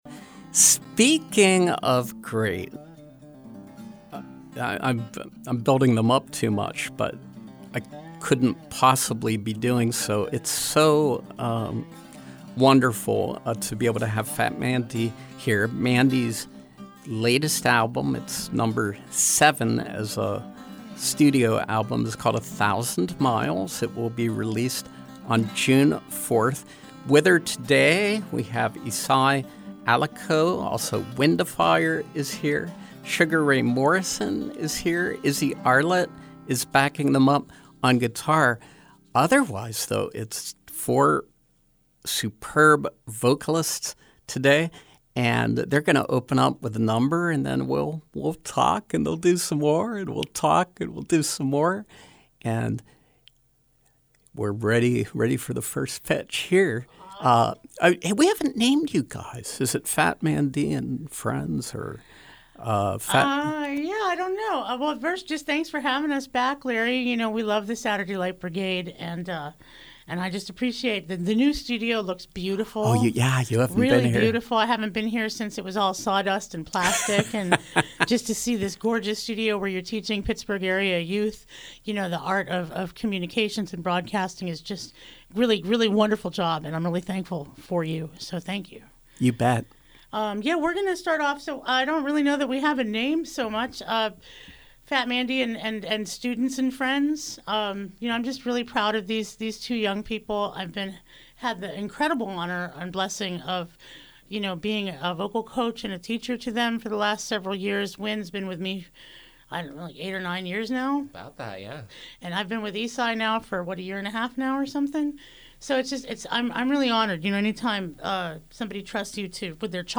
Cabaret Jazz vocalist